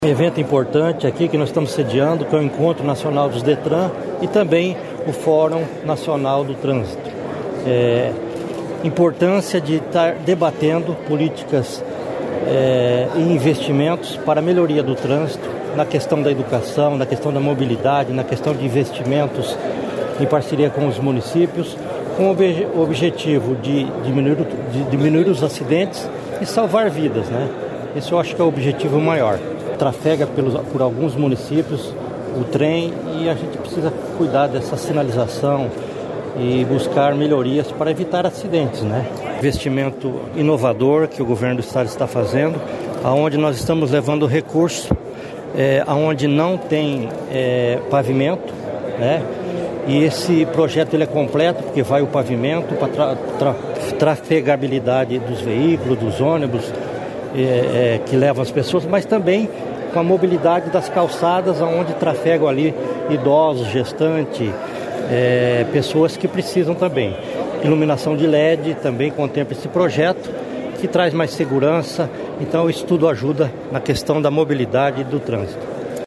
Sonora do chefe da Casa Civil do Paraná, João Carlos Ortega, sobre o 78º Encontro Nacional dos Detrans